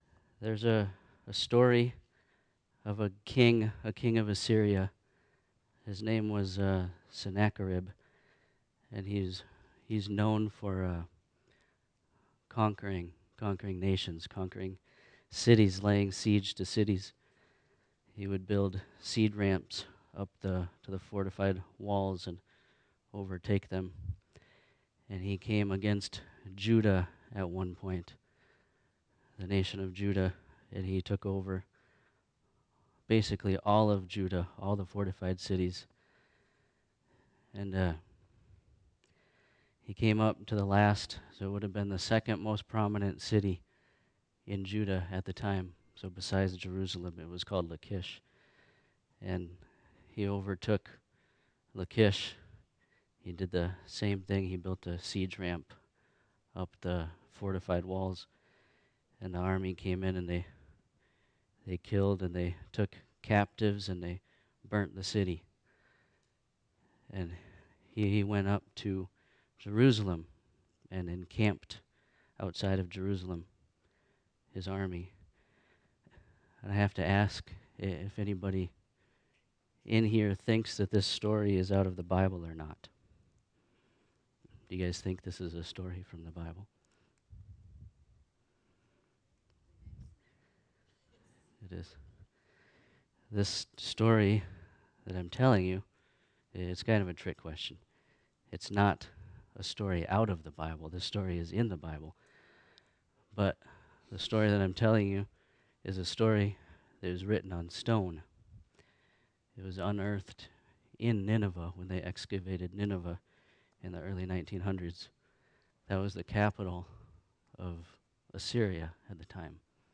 This is a special class-series Friendship church is providing for all who want to not just say they believe in God, but to prove He exists.